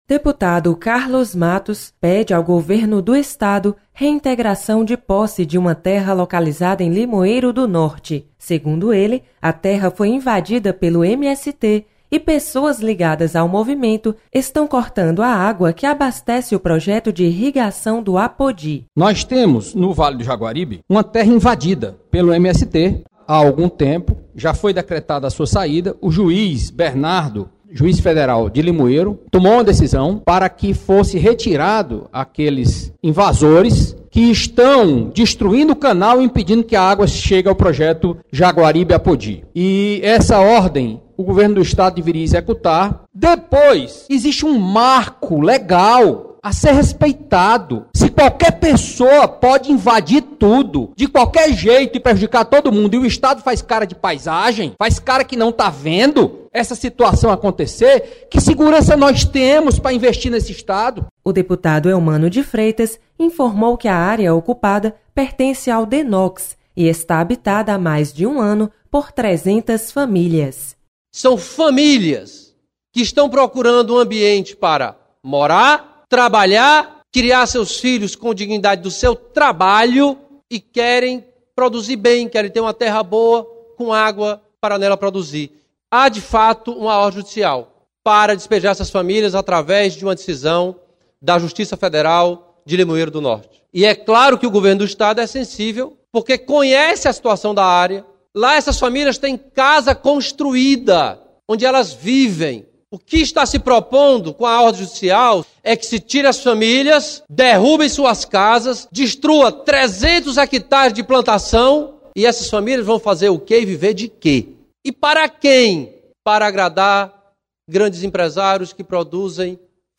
Você está aqui: Início Comunicação Rádio FM Assembleia Notícias Terra